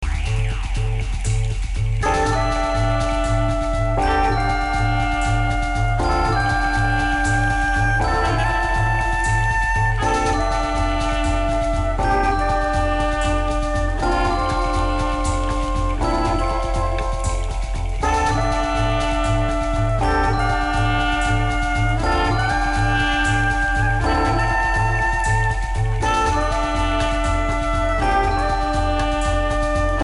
sassofoni, clarinetto
pianoforte, elettronica
contrabbasso
batteria, elettronica
tromba, elettronica